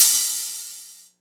• Cymbal Sound Clip G Key 05.wav
Royality free cymbal sample tuned to the G note. Loudest frequency: 7969Hz
cymbal-sound-clip-g-key-05-Dkb.wav